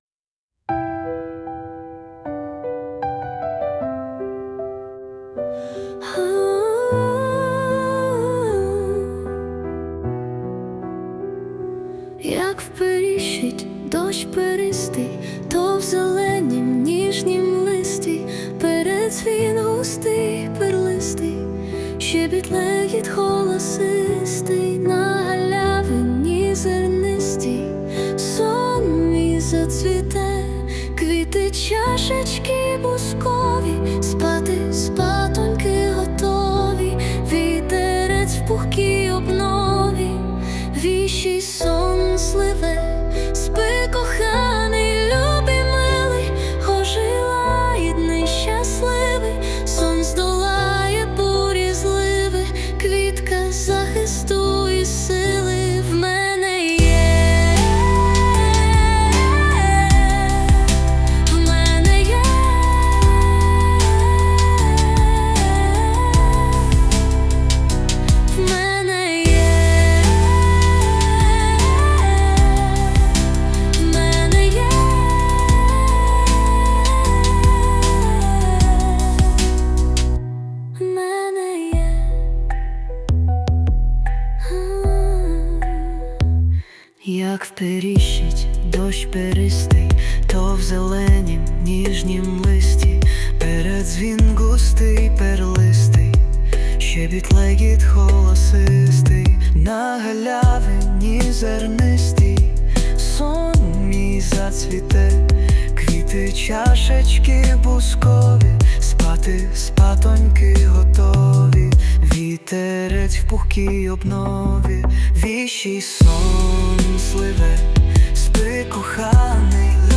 ВИД ТВОРУ: Вірш
12 12 16 Прекрасні слова! sp Майстерно підібране музичне супроводження.